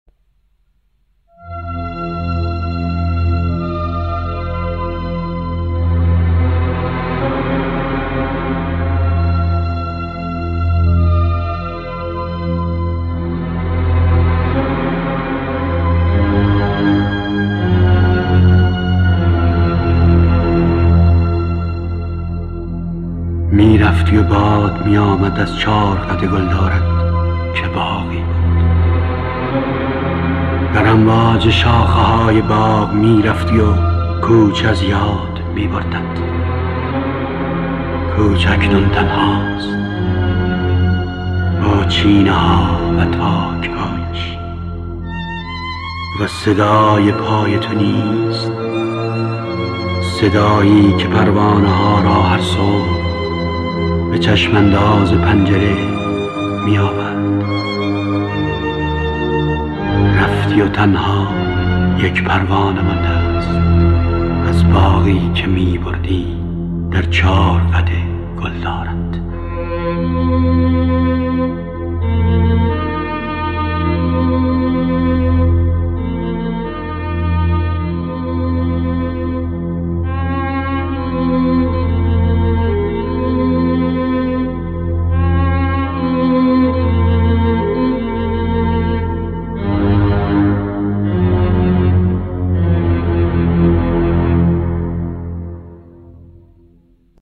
دانلود دکلمه ردی از خاطره با صدای احمدرضا احمدی همراه با متن
گوینده :   [احمدرضا احمدی]
آهنگساز :   آلبرت آراکلیان